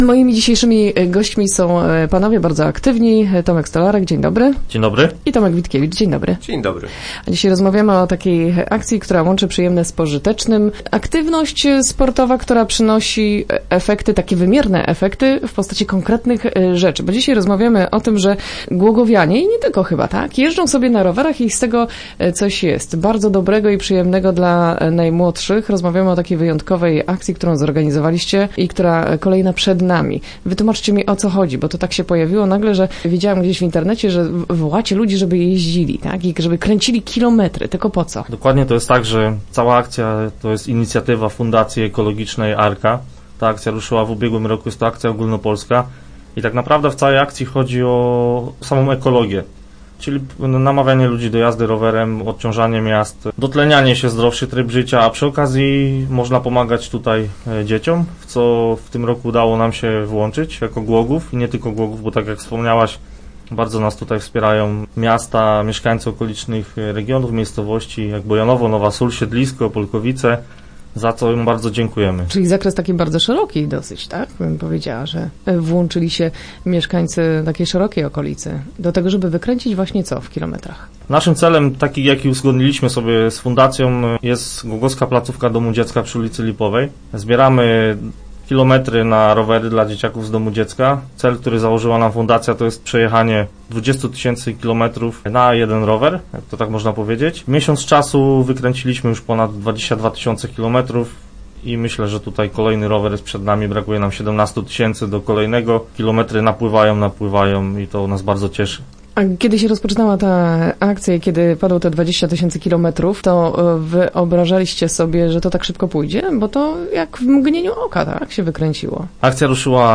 Za wspólnie przejechane na rowerach kilometry Fundacja Ekologiczna Arka przekazuje rower dla domu dziecka. O szczegółach akcji nasi goście opowiedzieli w poniedziałkowych Rozmowach Elki.